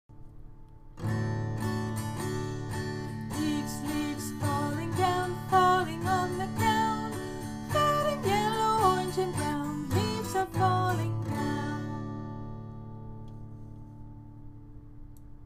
DOMAIN(S) Cognitive Development and General Knowledge (CD) INDICATOR(S) CD-SC3.4a Tune: “Row, Row, Row Your Boat” Leaves, leaves falling down, Falling on the ground.